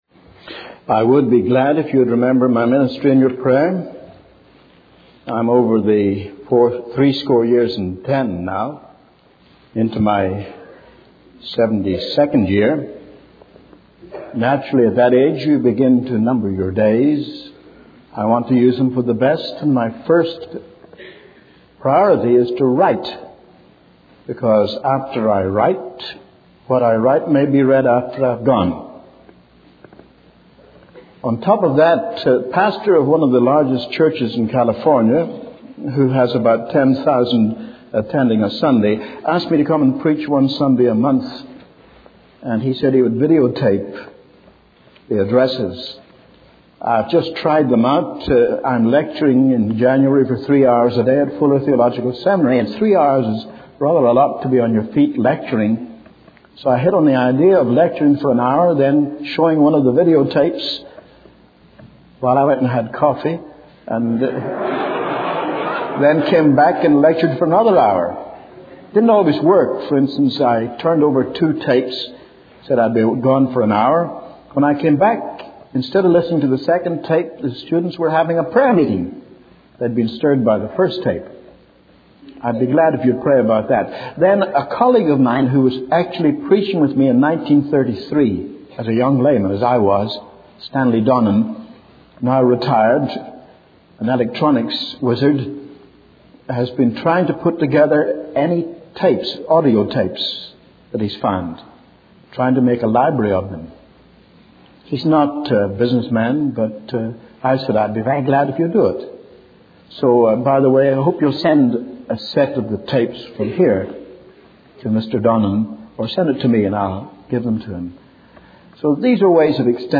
In this sermon, the preacher emphasizes the importance of repentance in the message of the gospel.